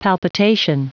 Prononciation du mot palpitation en anglais (fichier audio)
Prononciation du mot : palpitation